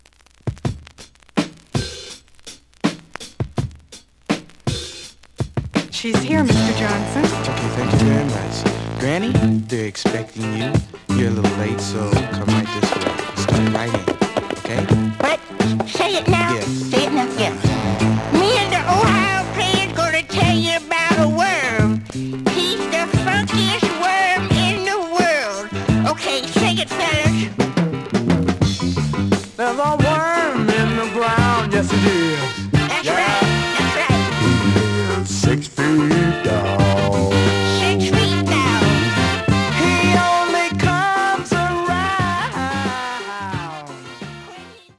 The audio sample is recorded from the actual item.
●Genre: Funk, 70's Funk
Slight damage on both side labels. Plays good.)